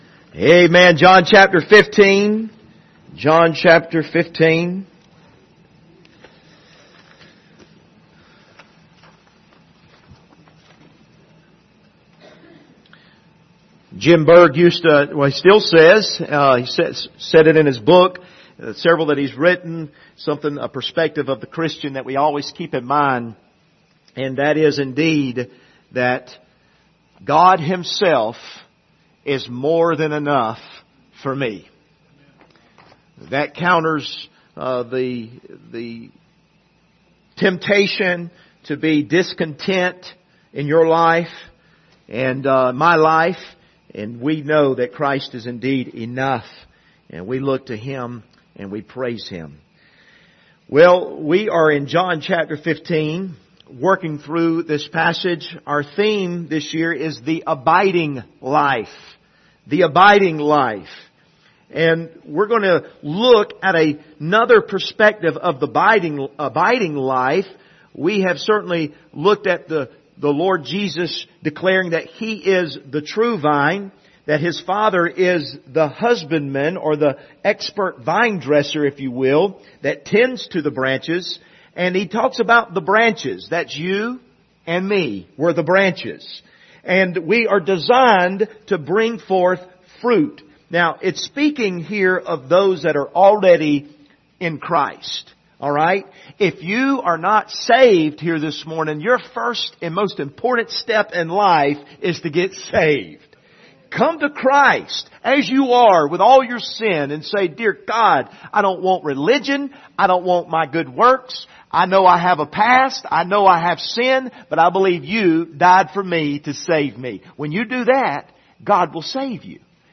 Passage: John 15:1-4, 7, 18-19 Service Type: Sunday Morning